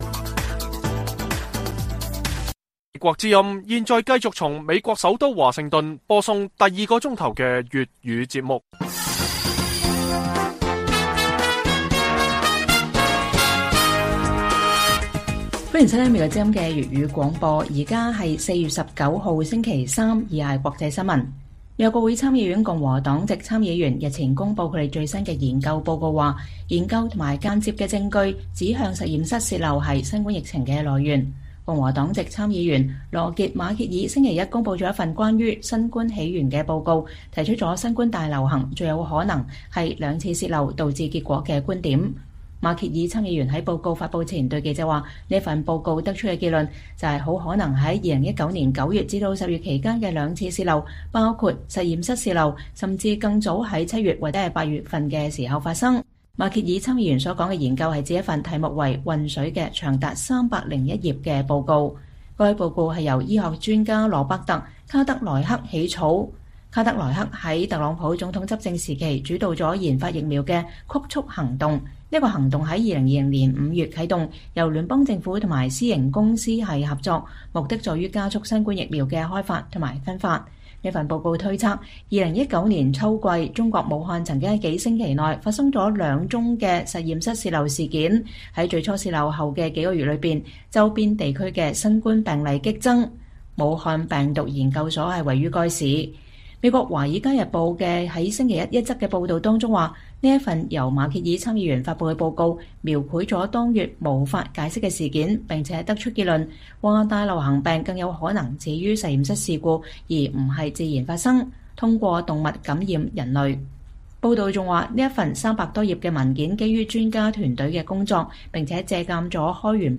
粵語新聞 晚上10-11點 : 中國在緬甸和柬埔寨擴張軍備將改變東南亞地緣政治風貌？